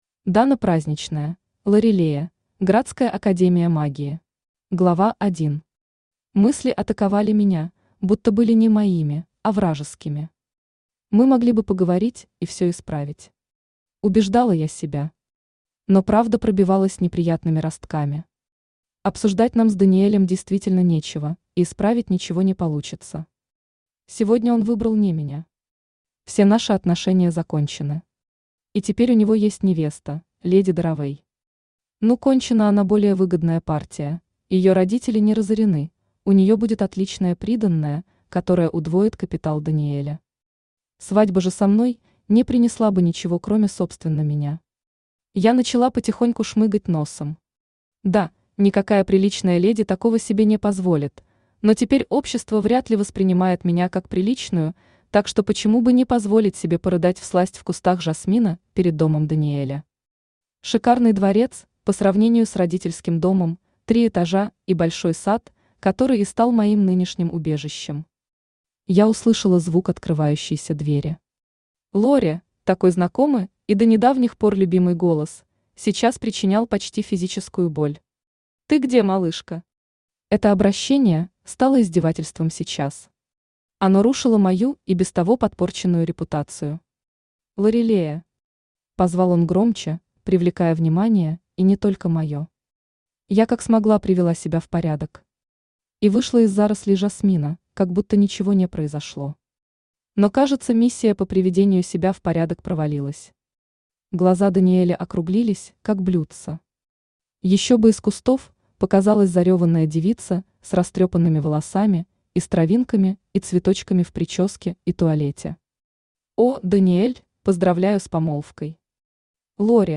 Аудиокнига Лорилея. Градская академия магии | Библиотека аудиокниг
Градская академия магии Автор Дана Праздничная Читает аудиокнигу Авточтец ЛитРес.